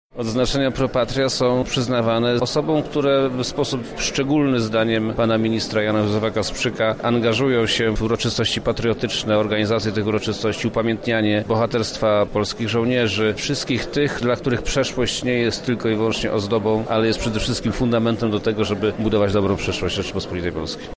W czasie uroczystości wręczono również odznaczenia „Pro Patria”, o których mówi wojewoda lubelski Przemysław Czarnek.